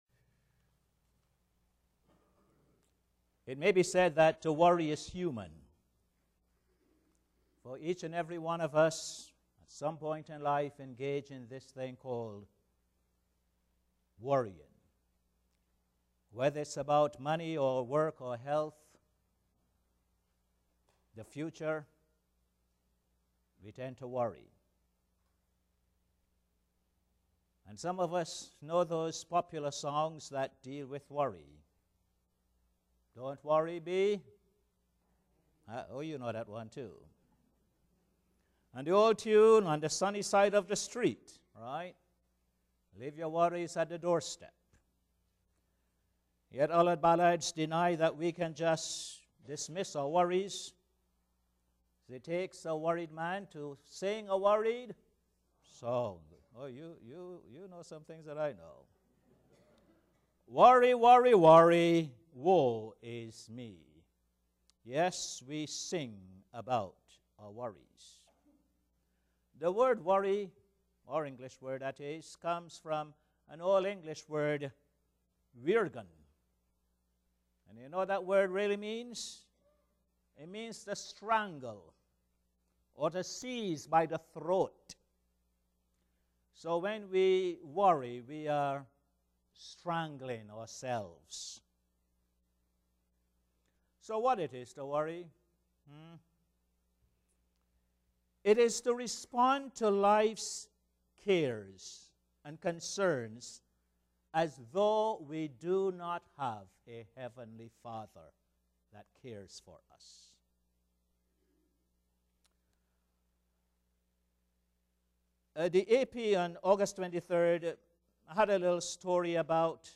Posted in Sermons on 30.